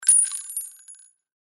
Звук рассыпавшихся патронов